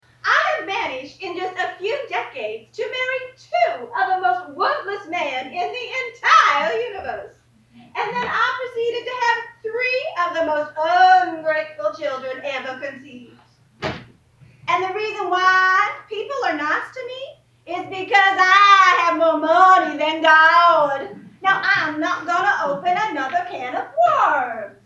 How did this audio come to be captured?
As part of the announcement, the cast of the Community Players’ upcoming production “Steel Magnolias” performed a brief scene for the assembled media and elected officials. The play is a comedy-drama by Robert Harling about the friendships of a group of women in Louisiana.